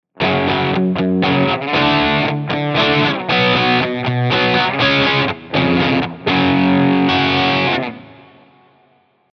• Végül jöjjön még egy riffesebb megközelítés
Itt ugyan nincsenek teljes akkordok, így csak a fogások (power chordok) alaphangjaira támaszkodhatunk: F#, D, H, C#, F.
kisriff.mp3